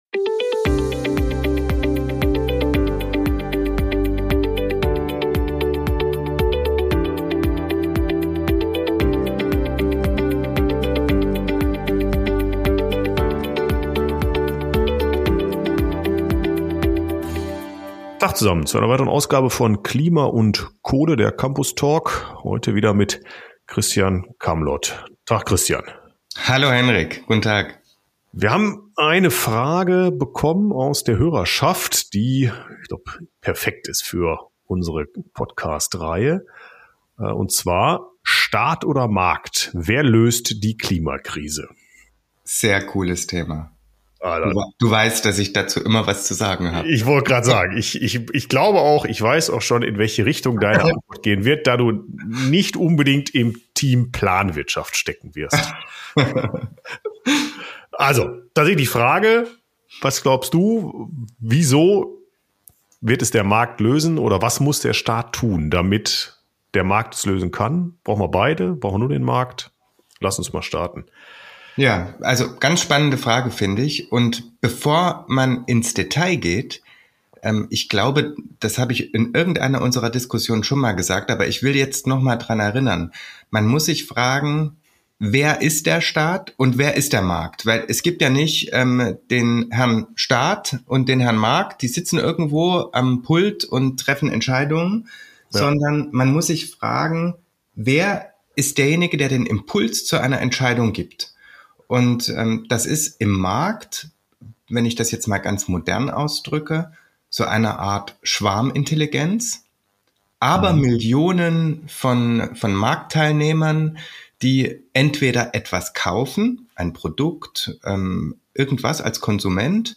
#56 Markt oder Staat - Wer löst die Klimakrise? Gespräch